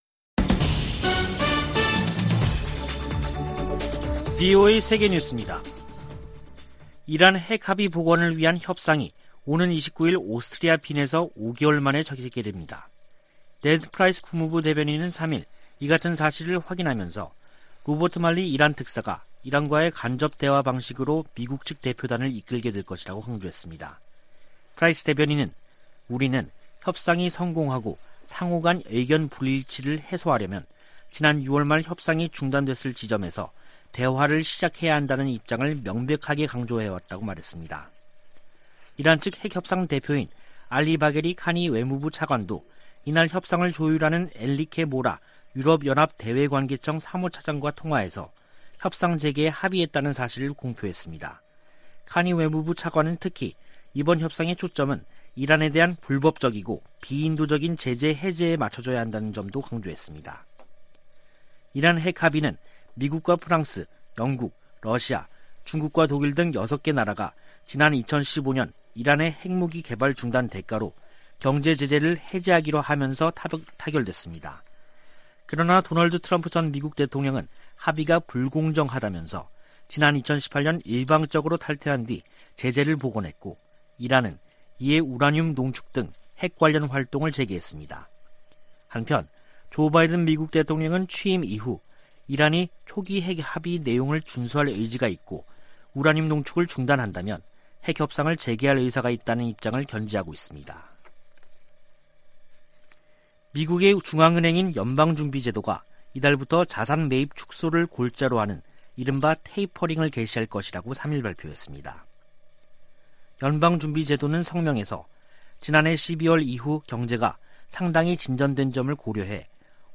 VOA 한국어 간판 뉴스 프로그램 '뉴스 투데이', 2021년 11월 4일 3부 방송입니다. 북한이 유엔총회에서 주한 유엔군사령부 해체를 다시 주장했습니다. 마크 밀리 미 합참의장은 북한 정부가 안정적이라며 우발적 사건이 발생하지 않을 것으로 내다봤습니다. 중국의 핵탄두가 2030년 1천개를 넘어설 수 있다고 미 국방부가 전망했습니다.